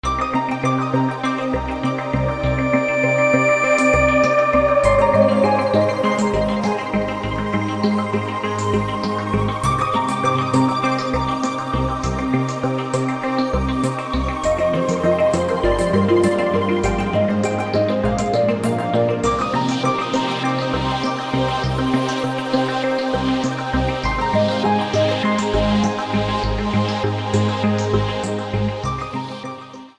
The music that plays underwater